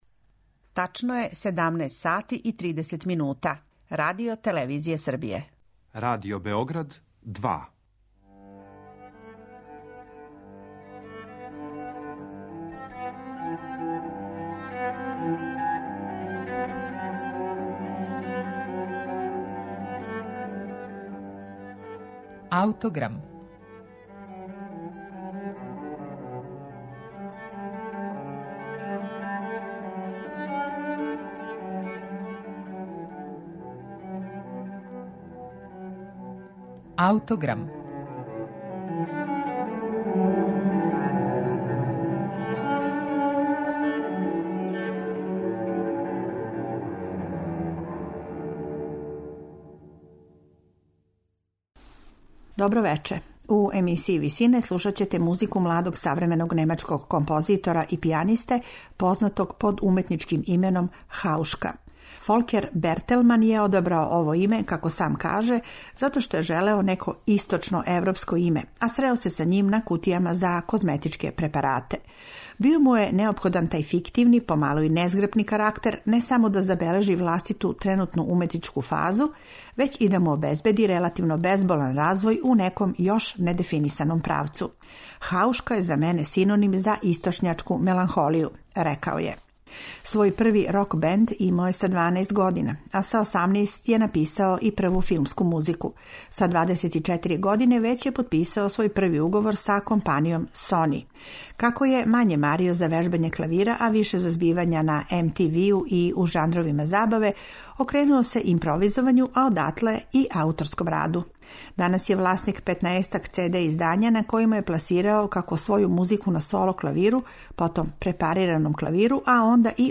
медитативне и духовне композиције
дела за препарирани клавир